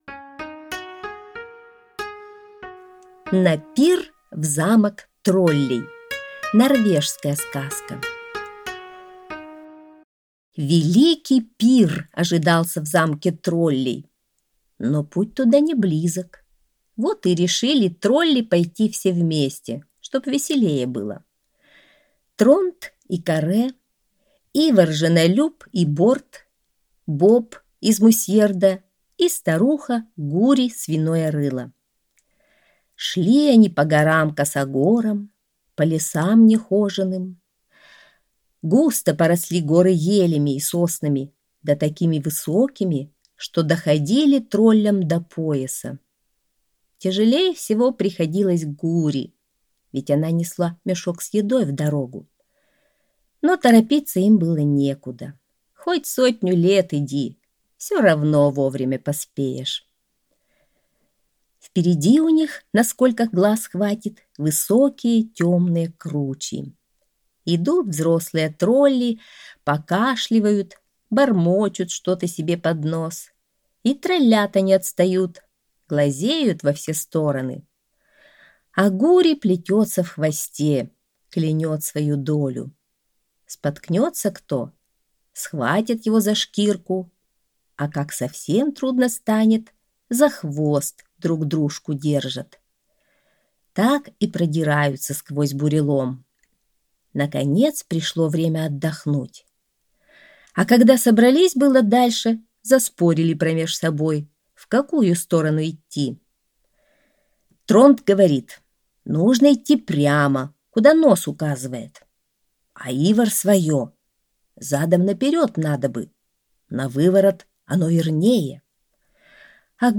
Аудиосказка «На пир в замок троллей»